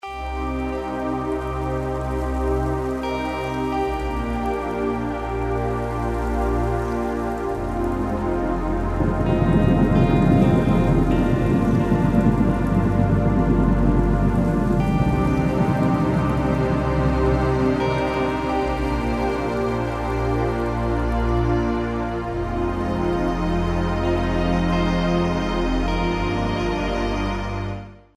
رینگتون موبایل ملایم و آرامش بخش